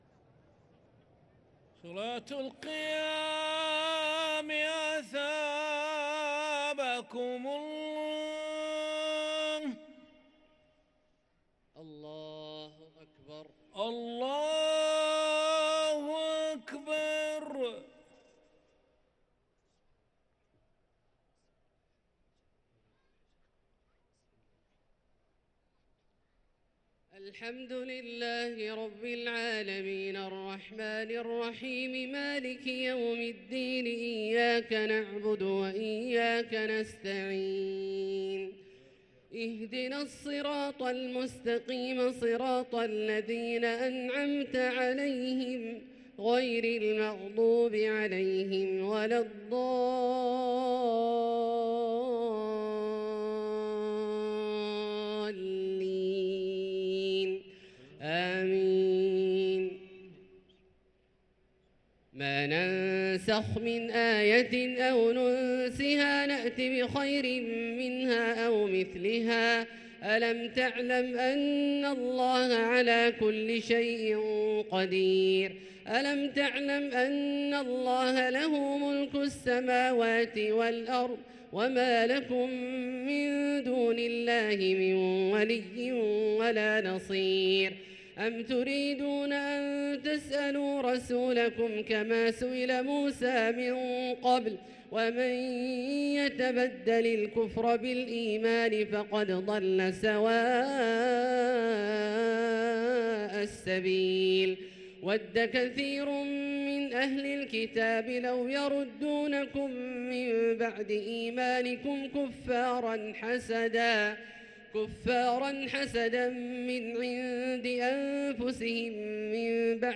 صلاة التراويح ليلة 2 رمضان 1444 للقارئ عبدالله الجهني - الثلاث التسليمات الأولى صلاة التراويح